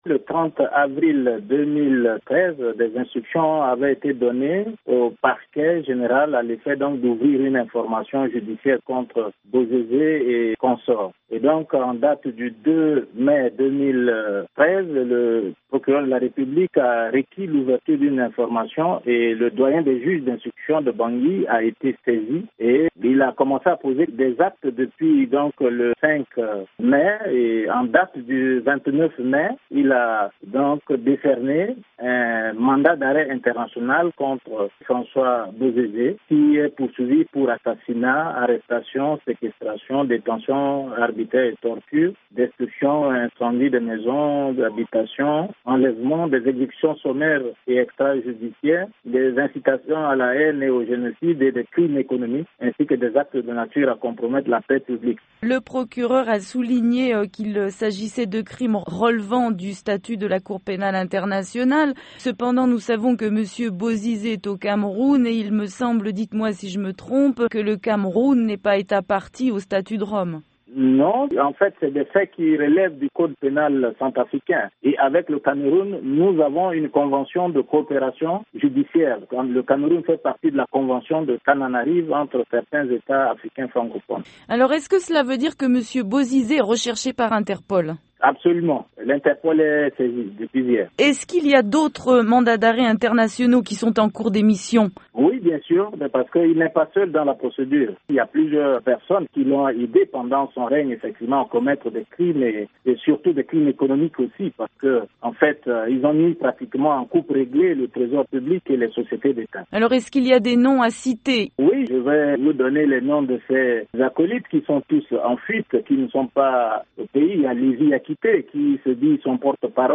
Le ministre Arsène Sendé au micro